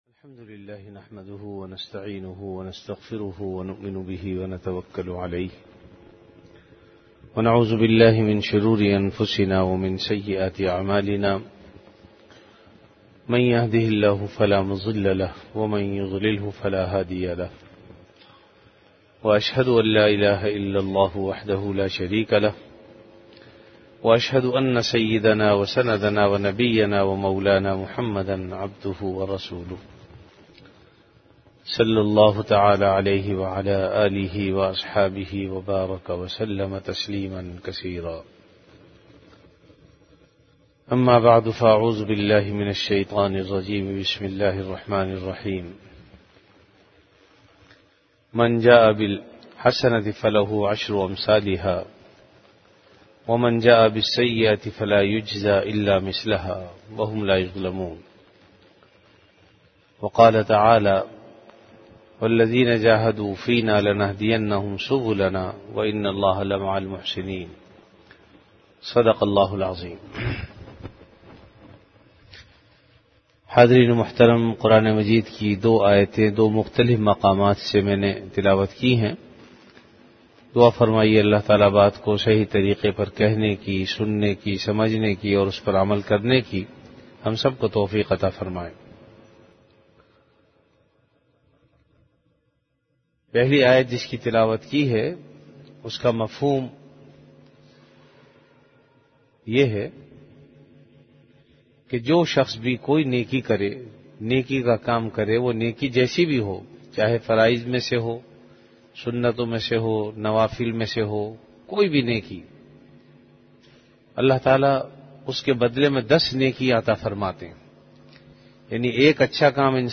Bayanat · Jamia Masjid Bait-ul-Mukkaram, Karachi